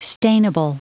Prononciation du mot stainable en anglais (fichier audio)
Prononciation du mot : stainable